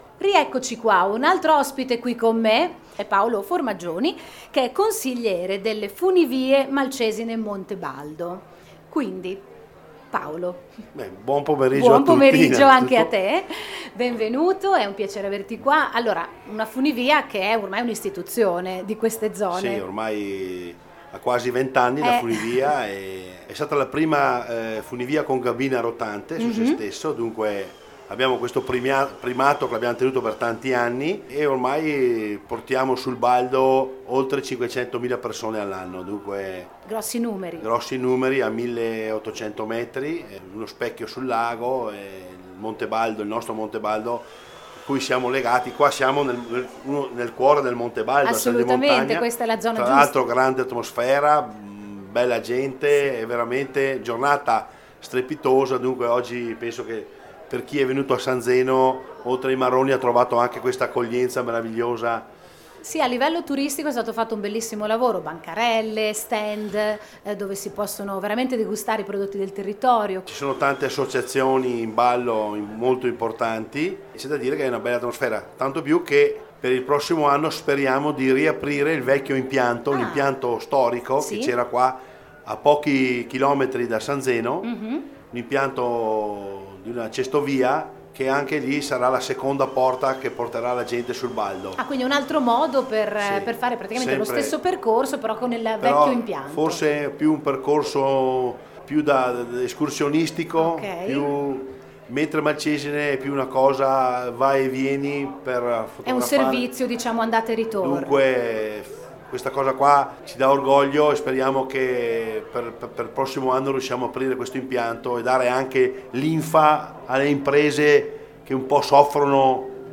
In collegamento dallo studio mobile